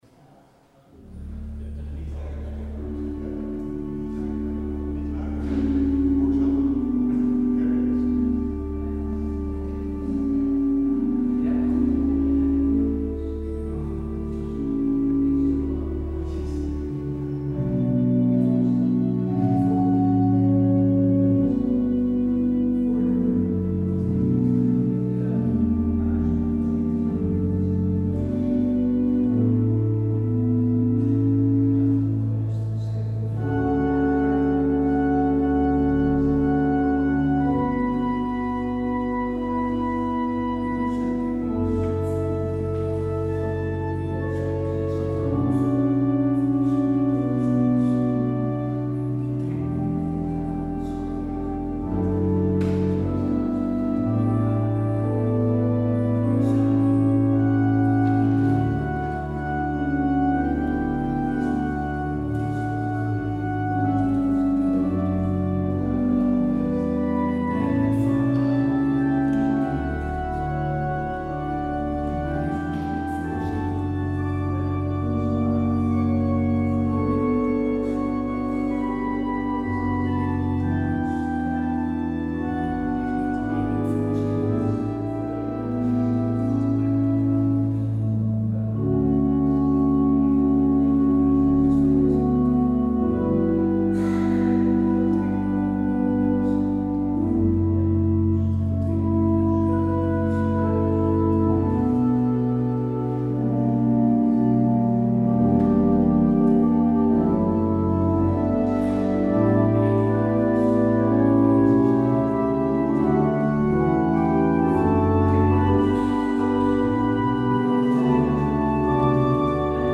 Luister deze kerkdienst hier terug
Het openingslied is: NLB 117d (3x).
Het slotlied is: NLB 981: 1, 2, 3, 4 en 5.